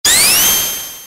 Sound Buttons: Sound Buttons View : Sonic Spindash
sonic-spindash.mp3